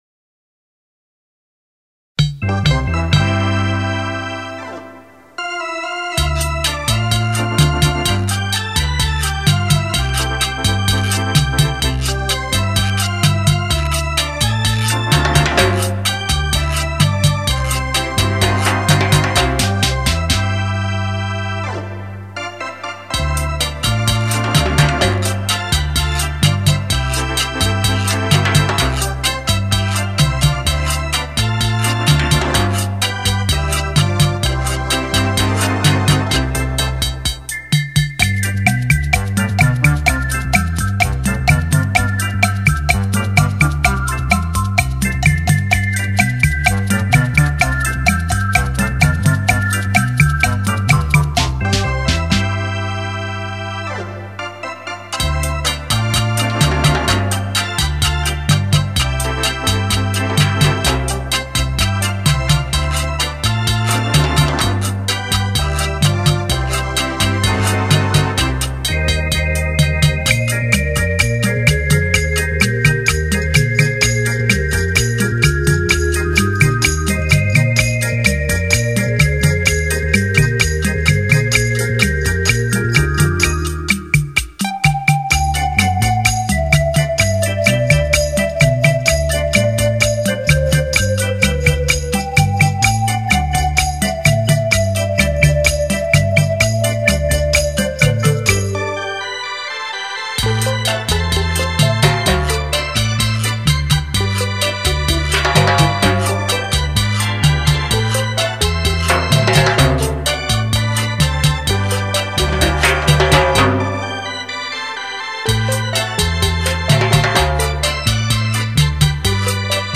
史上最强720度环绕立体声
360度超炫立体音效玩弄音乐于股掌